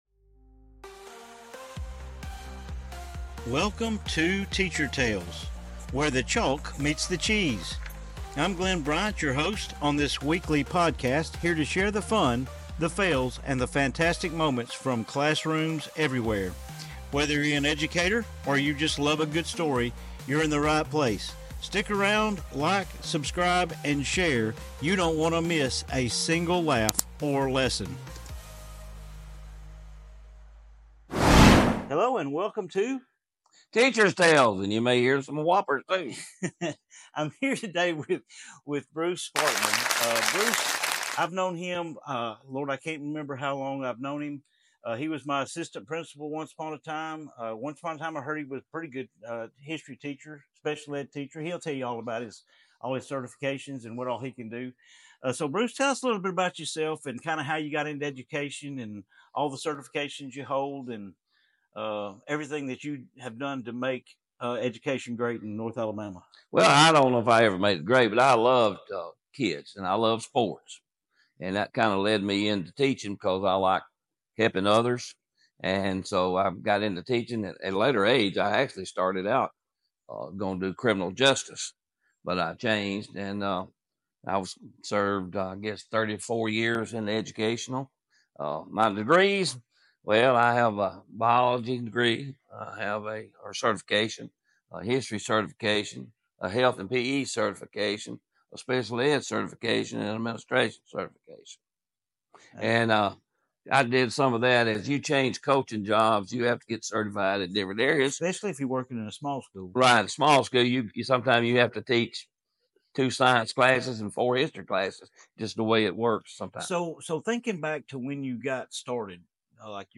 🎯😂 Tune in for a lighthearted conversation about the joys and challenges of being a principal, and hear some of the clever tricks his students pulled over the years.